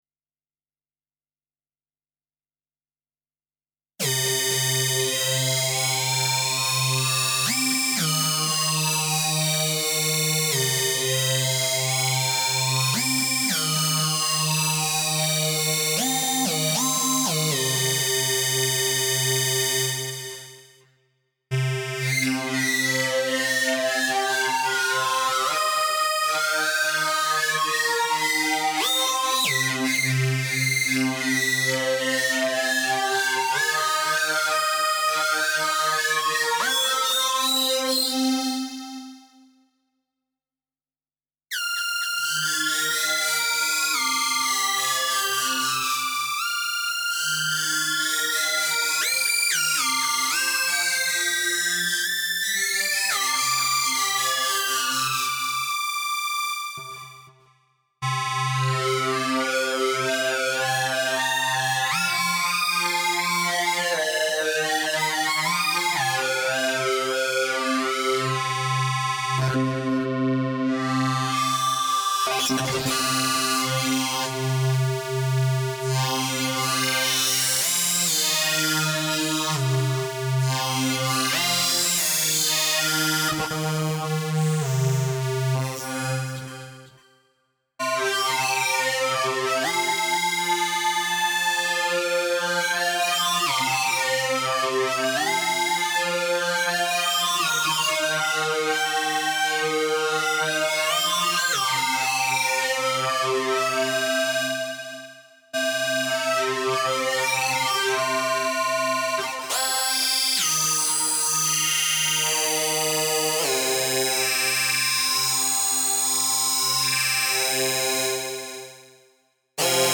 SOUND good allrounder. Effects flag ship! sort of studio standard especially for trance and neo EBM stuff
some audio, not so nice but demoes wavetables, filters and envs..
nicht "schön" aber demonstriert Wavetables, Hüllkurven Filter..